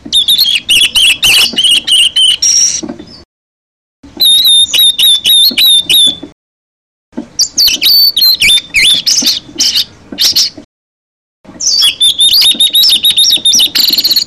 さえずりのコーナー（オオルリ編）
親鳴き準備中 197KB 親鳥になったら、さえずりが変わりました